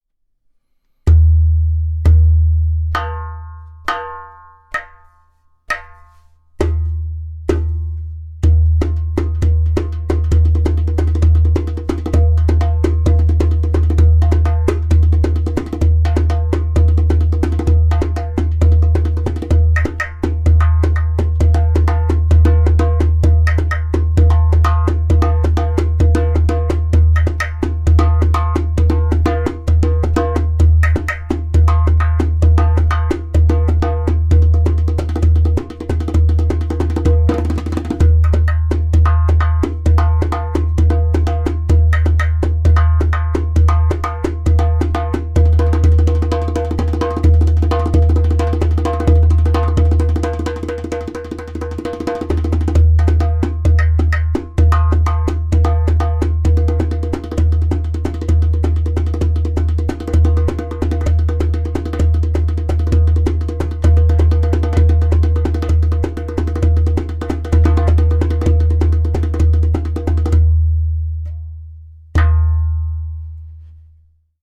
130bpm
• High pitch tak paired with deep bass.
• Strong and super easy to produce clay kik (click) sound
• Beautiful harmonic overtones.